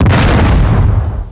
Explode1.wav